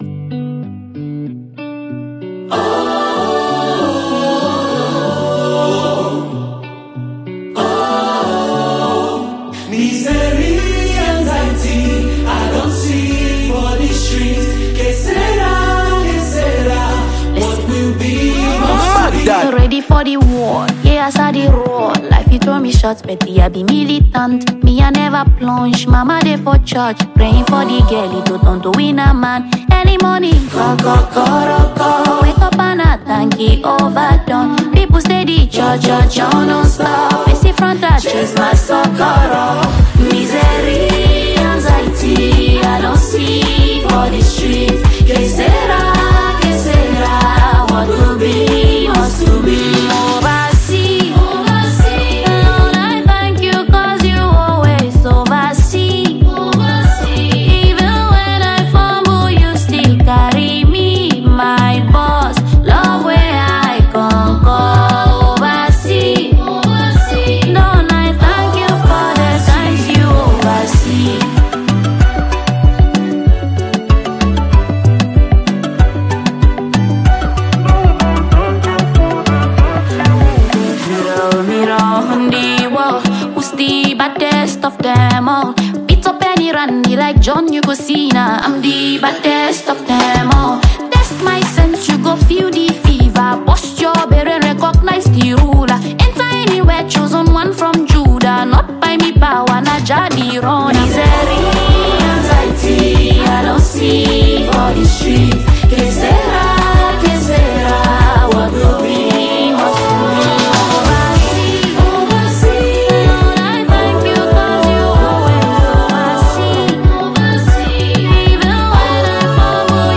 A-talented Ghanaian female Singer And Songwriter